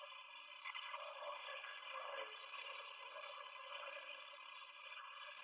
Electronic Voice Phenomena (EVP)
Caught this one in cell block 12 at Eastern State Penitentiary.